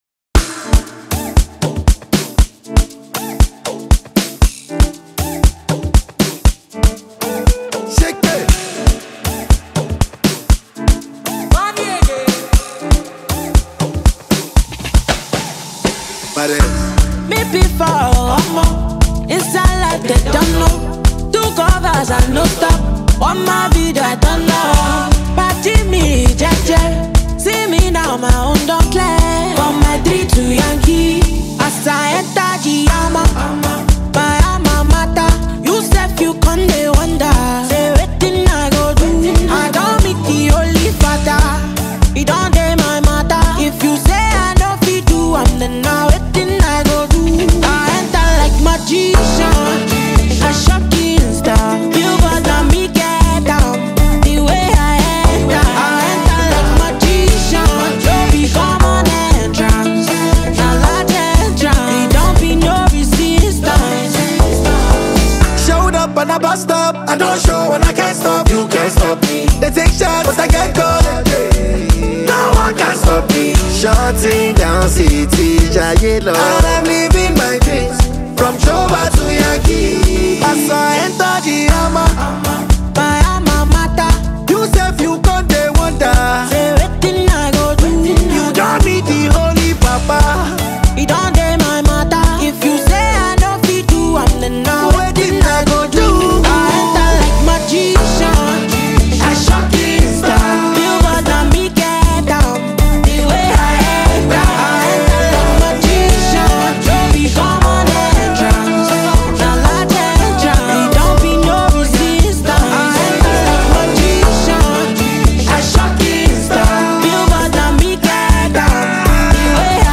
Veteran fast-rising singer